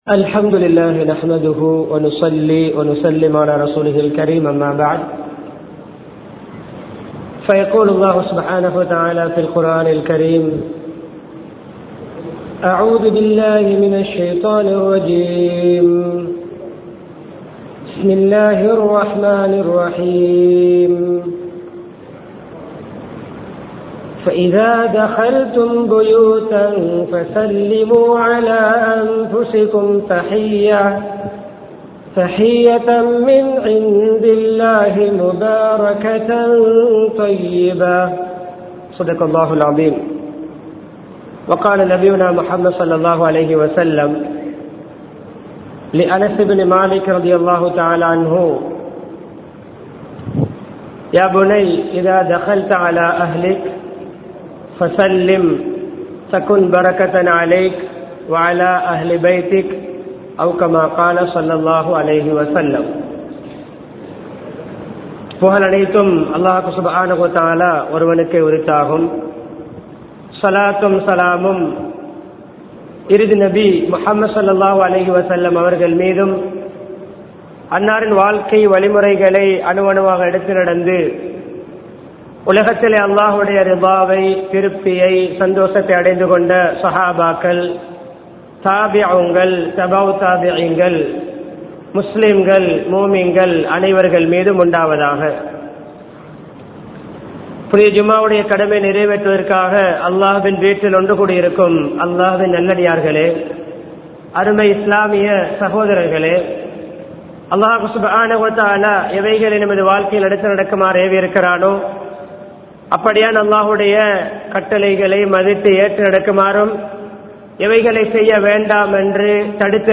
வீட்டின் ஒழுக்கங்கள் | Audio Bayans | All Ceylon Muslim Youth Community | Addalaichenai
Malwana, Raxapana Jumua Masjidh